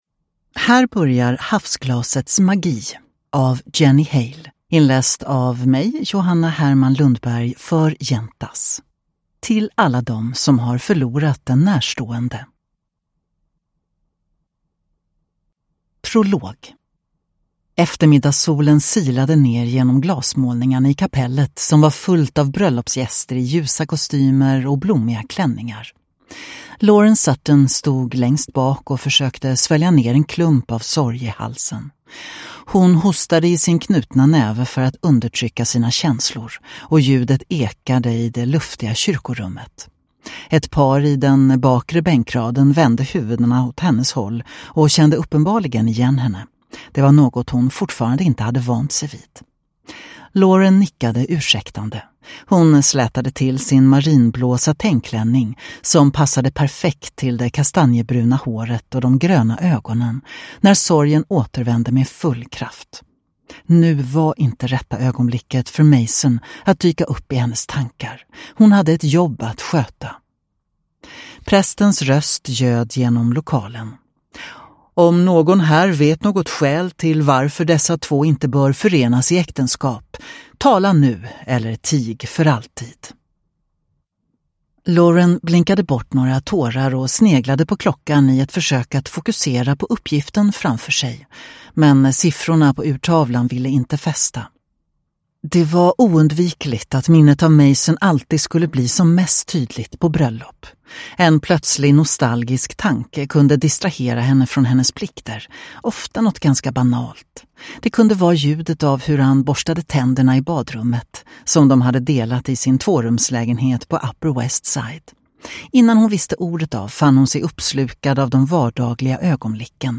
Havsglasets magi – Ljudbok